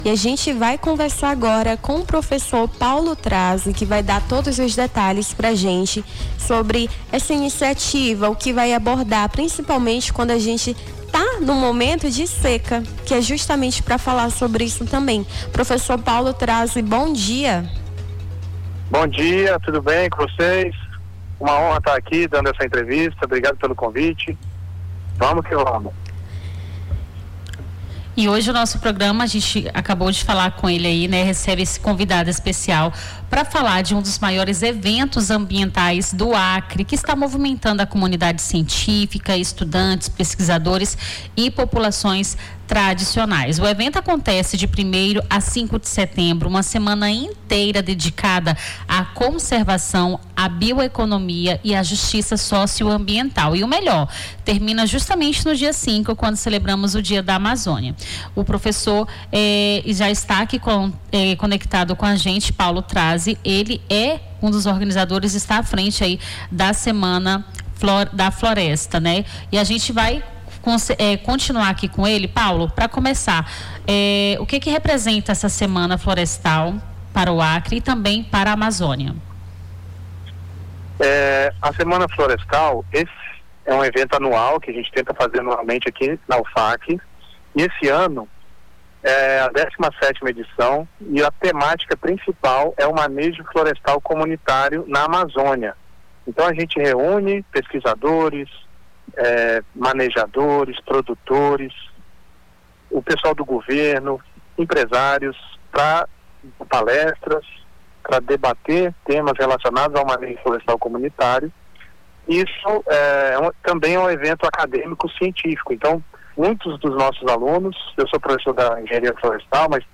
Nome do Artista - CENSURA - ENTREVISTA (SEMANA DA FLORESTA) 01-09-25.mp3